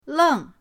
leng4.mp3